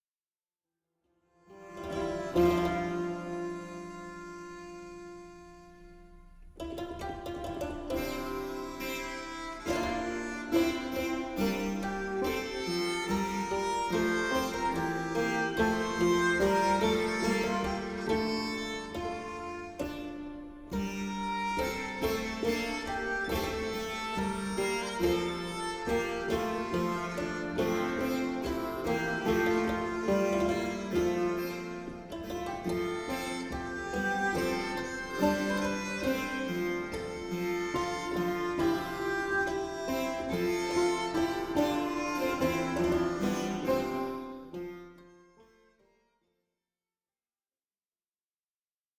Polyphonies courtoises
harpe, vièle, luth, rebec et clavicythérium